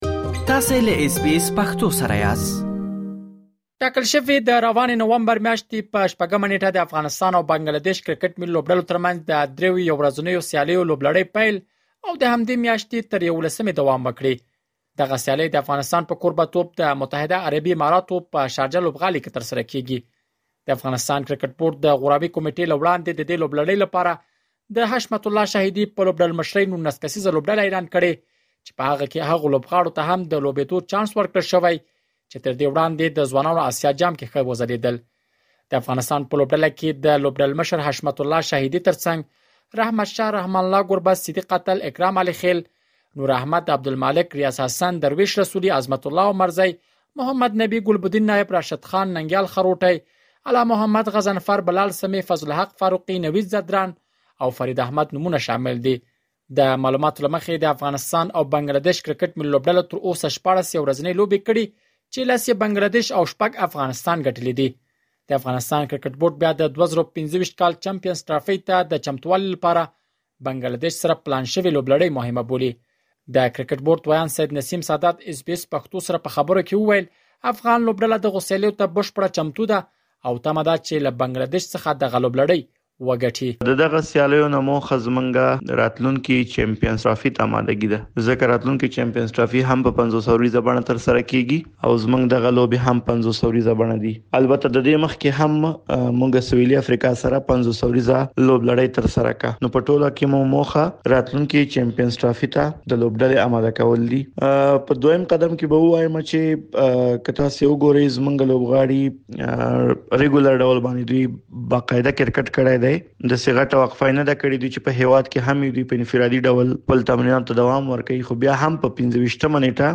مهرباني وکړئ لا ډېر معلومات دلته په رپوټ کې واوروئ.